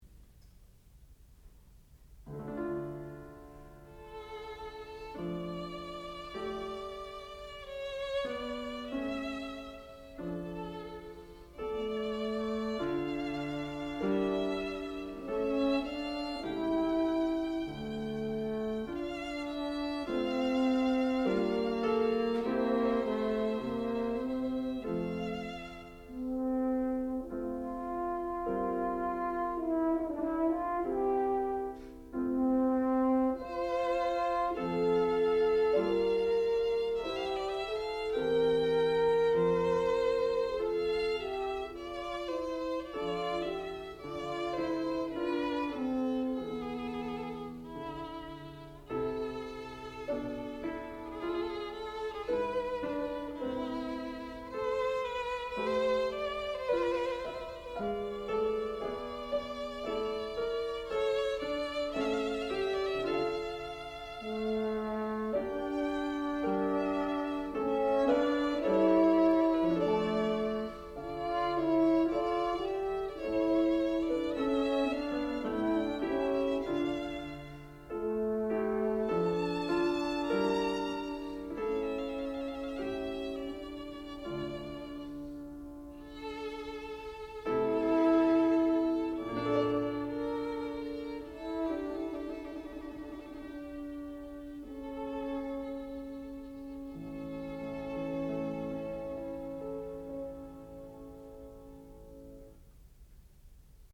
sound recording-musical
classical music
violin
piano
horn